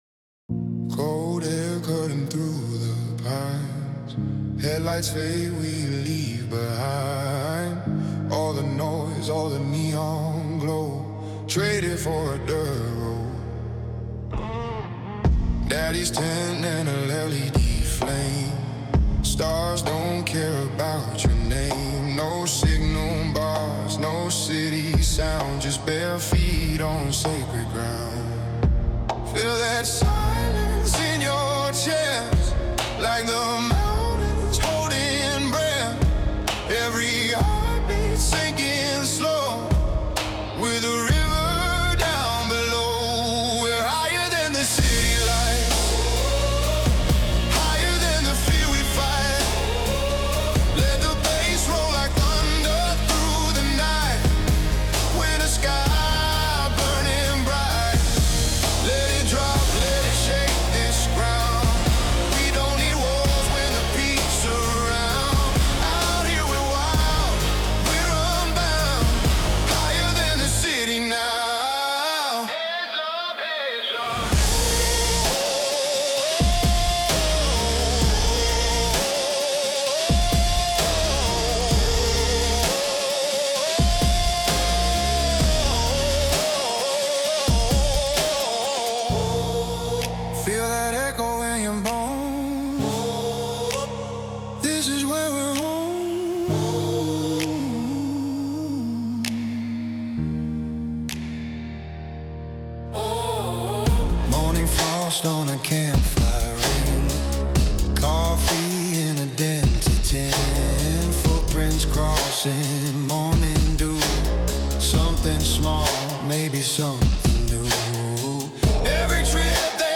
Official Studio Recording
a fusion of 140 bpm cinematic soul and gritty outlaw country